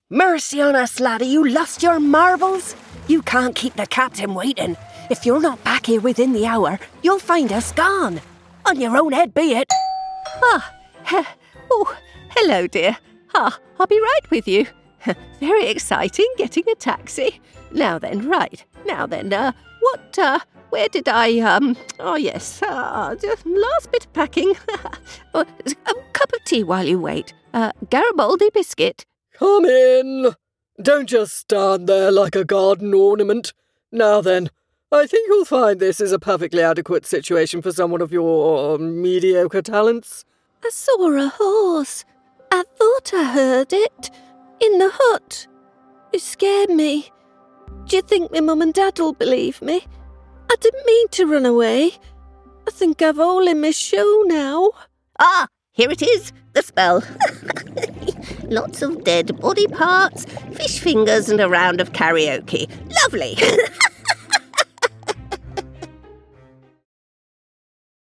Maduro, Comercial, Versátil, Cálida, Empresarial
Su voz es descrita como confiable, juguetona, inteligente y cercana.